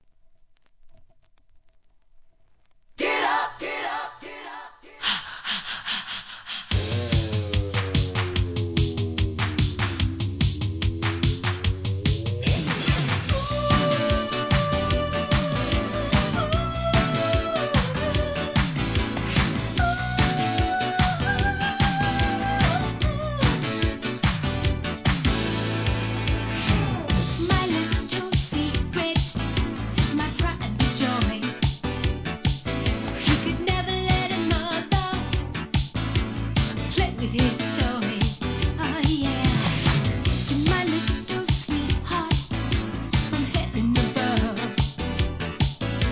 ¥ 440 税込 関連カテゴリ SOUL/FUNK/etc...